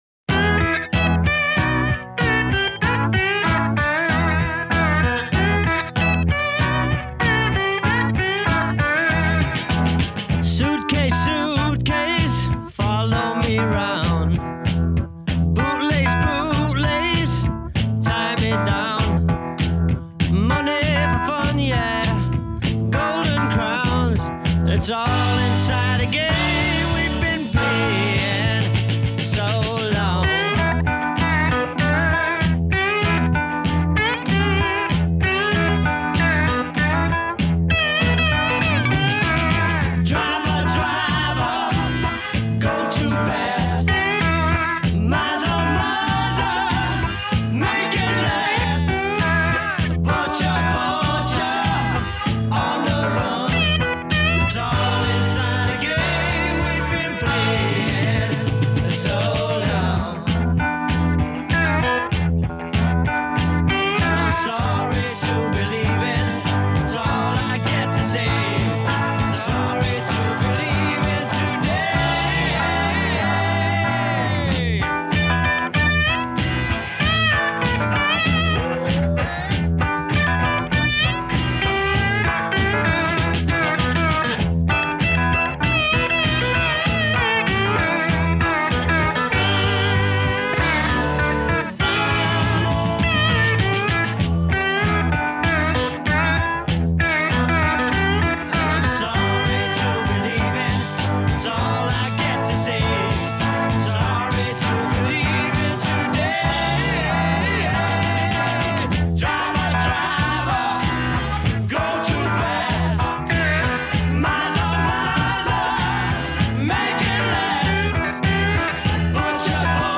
Here’s the studio version: